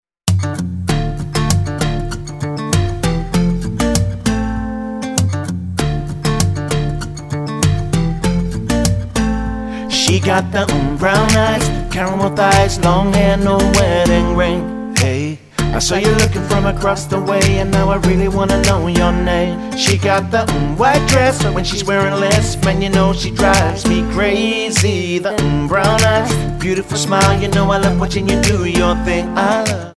--> MP3 Demo abspielen...
Tonart:B Multifile (kein Sofortdownload.